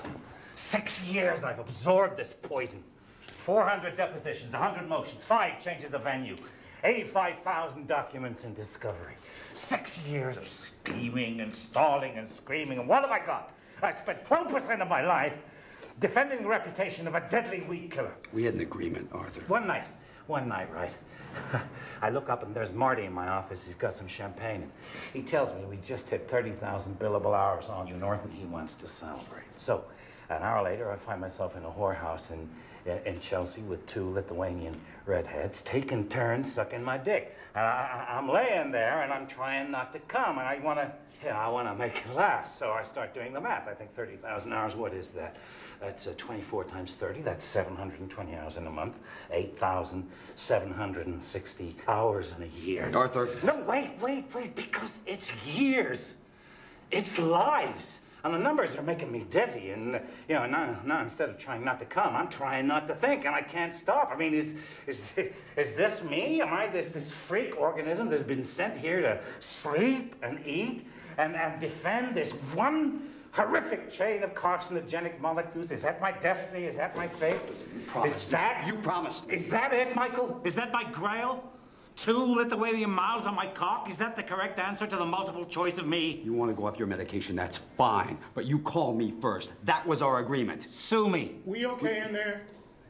In this scene, Michael Clayton (played by Clooney) and Arthur Edens (played by Wilkinson) have an argument in prison.